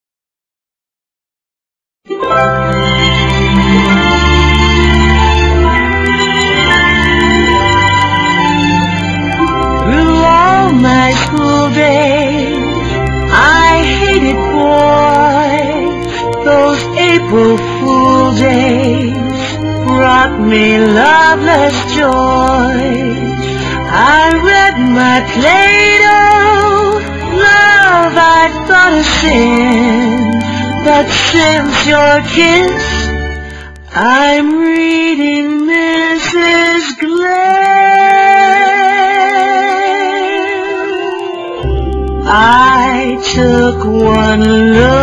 NOTE: Vocal Tracks 1 Thru 11